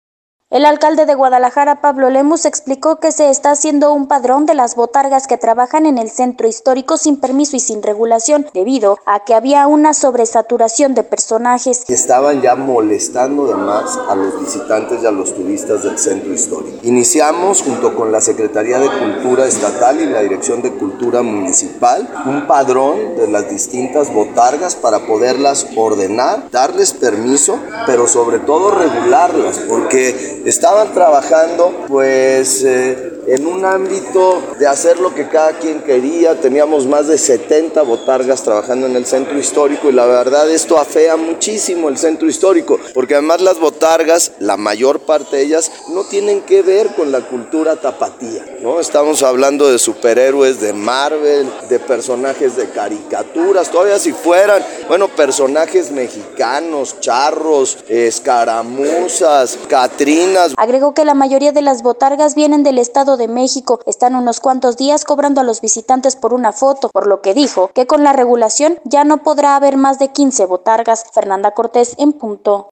El presidente municipal de Guadalajara, Pablo Lemus explicó que están haciendo un padrón de las botargas que trabajan en el Centro Histórico sin permiso y sin regulación, debido a que había una sobresaturación de personajes.